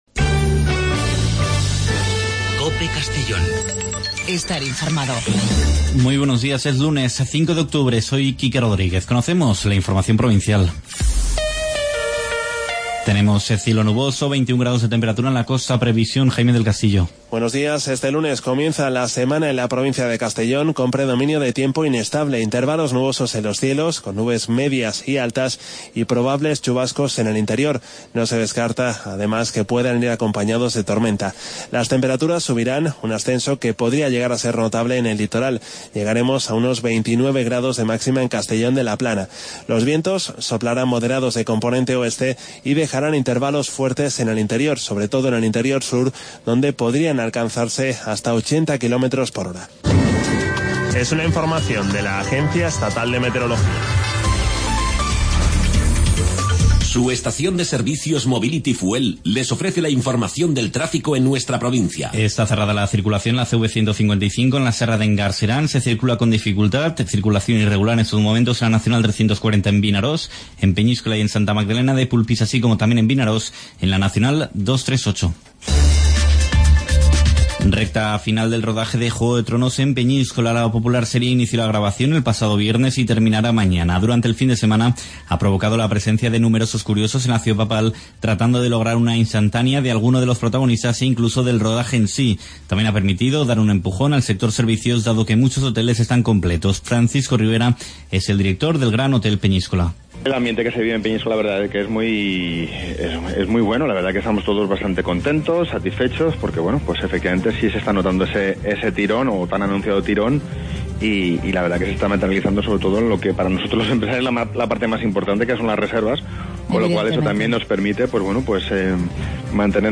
Informativo a nivel provincial con los servicios informativos de COPE en Castellón.